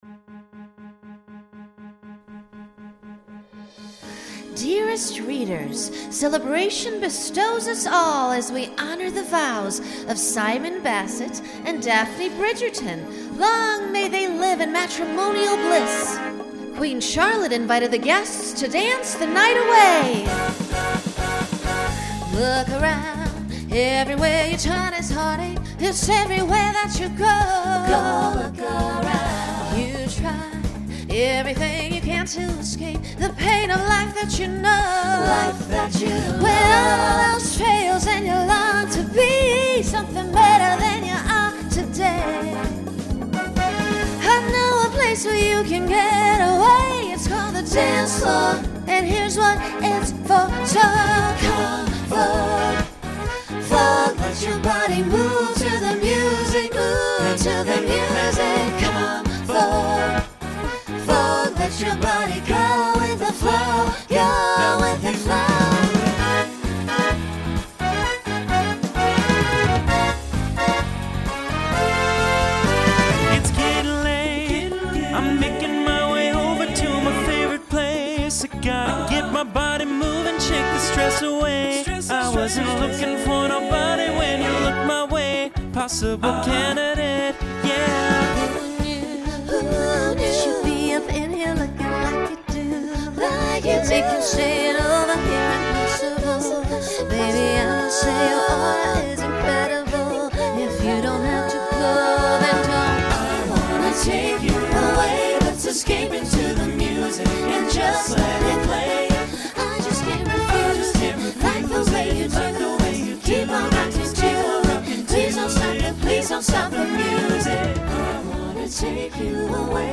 Includes custom bow.
Genre Pop/Dance
Voicing SATB